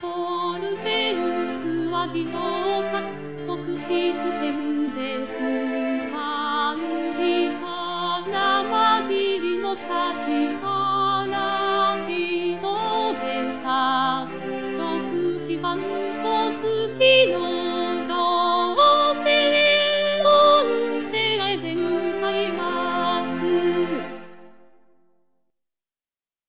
「スタイル：校歌風」
自動で作曲し、伴奏つきの
合成音声で歌います。